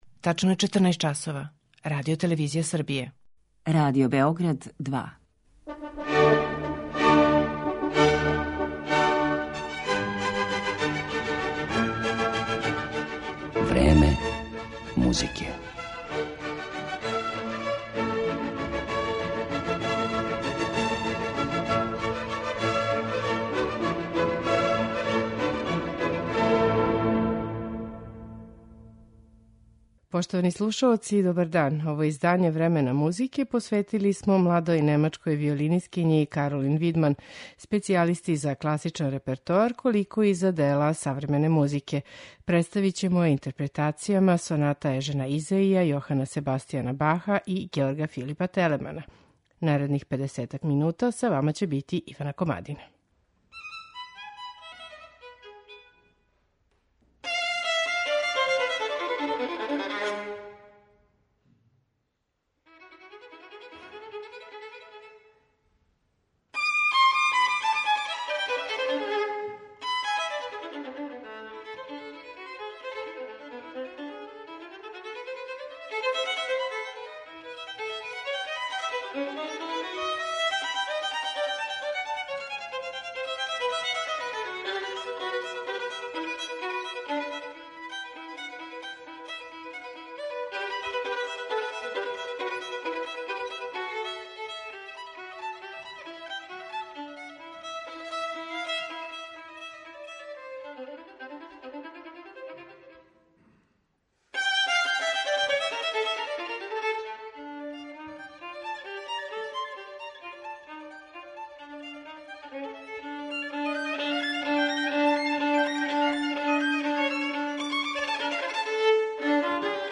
Представићемо немачку виолинисткињу Каролин Видман
Радим јер сматрам да је много композиција генијално" - тврди немачка виолинисткиња Каролин Видман.
У Времену музике, Каролин Видман представићемо интерпретацијама дела Ежена Исаија, Георга Филипа Телемана и Јохана Себастијана Баха.